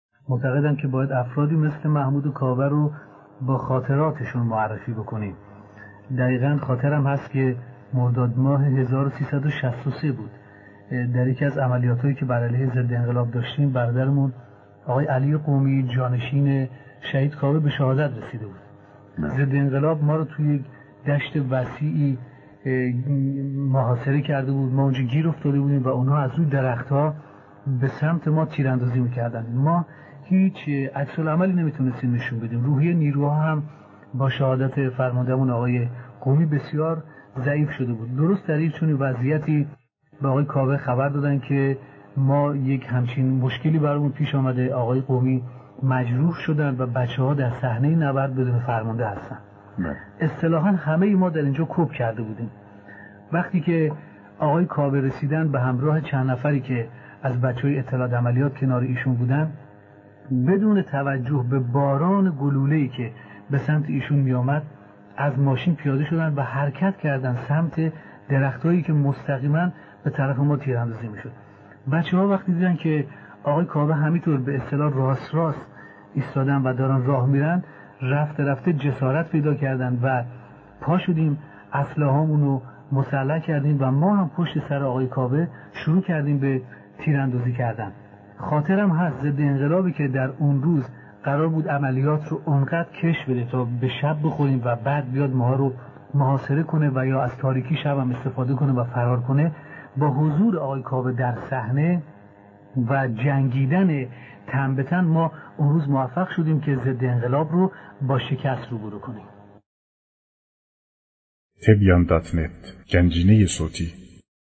گلف چند رسانه‌ای صوت روایتگری معرفی شهیدکاوه با بیان خاطرات معرفی شهیدکاوه با بیان خاطرات مرورگر شما از Player پشتیبانی نمی‌کند.